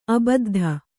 ♪ abaddha